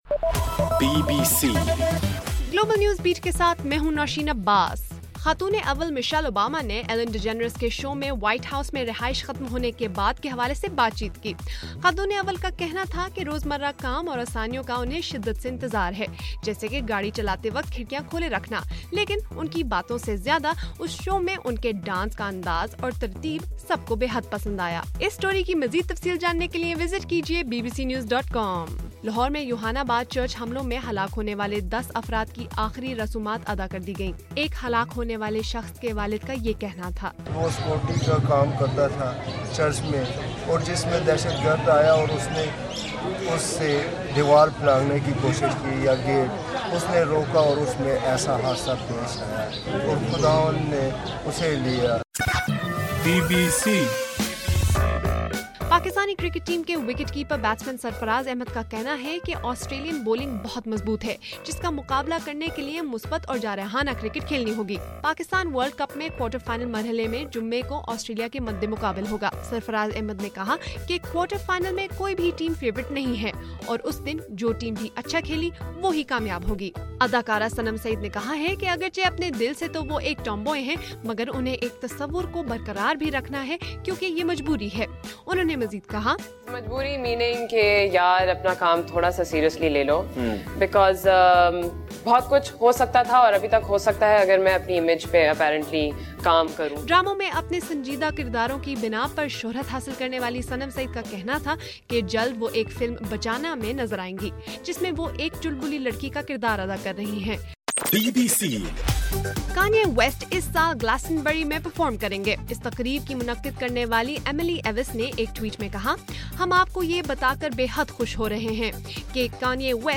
مارچ 17: رات 12 بجے کا گلوبل نیوز بیٹ بُلیٹن